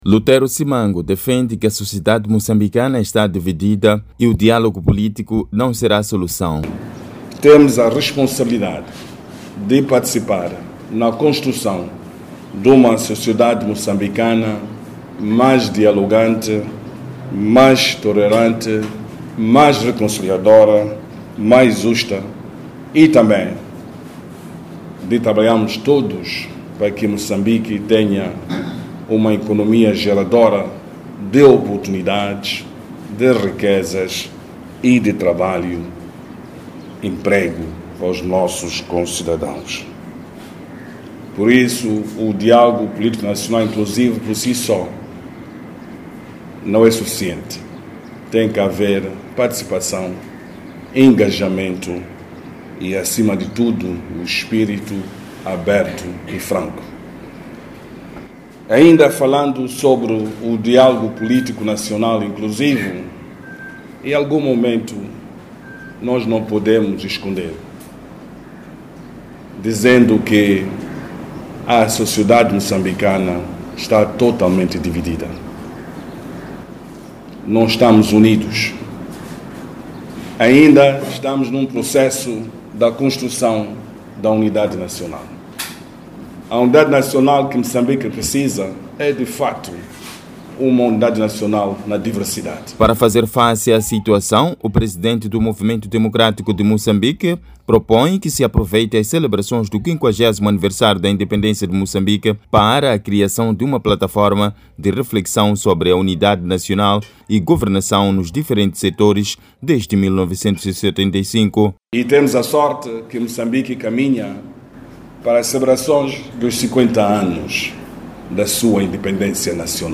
Lutero Chimbirombiro Simango, Presidente do MDM, falava na terça-feira, 16 de Abril na delegação política provincial do partido, durante o balanço da sua visita de trabalho em Sofala.